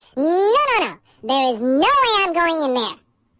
描述：一个人声线的记录和处理，就像一个卡通花栗鼠说的一样。 录音是在Zoom H4n上进行的。使用MOTU Digital Performer中的Spectral Effects进行操纵